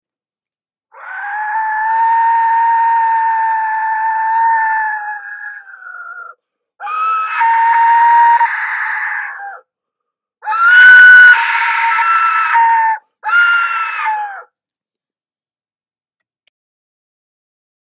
Aztec Death Whistle Professional series
This whistle is loud and has an over pitch screeching sound. excellent sound.
Large Aztec Death whistle
The Aztec Death Whistle, hand tuned to produce the most frightening scariest sound.  This whistle’s sound is just incredible, click the play button for the sound of this specific whistle, you will be getting this specific whistle pictured that produces this low pitched growling screaming sound, sound was not alter what so ever, it is wild.
The Aztec Death Whistle is a hand crafted musical instrument producing the loudest, scariest, terrifying sound around.